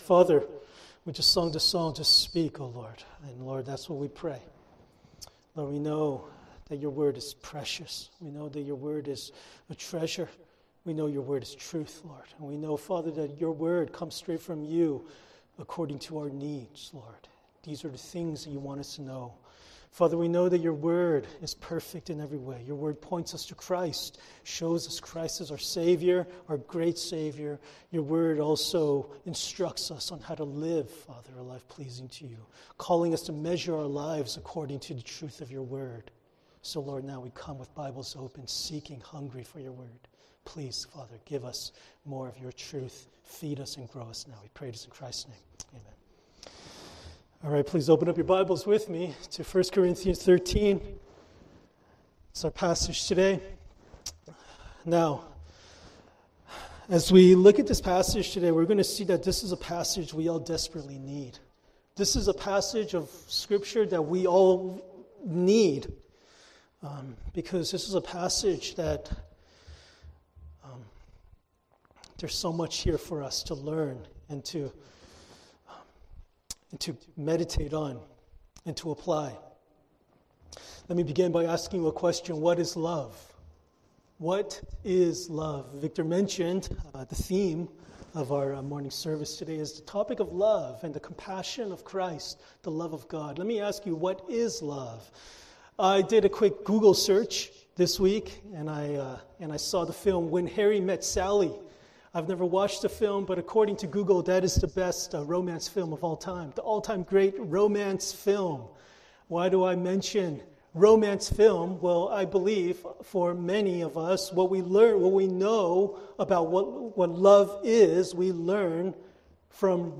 1 Corinthians 13:4-7 Service Type: Sunday Worship 1 Corinthians Chapter 13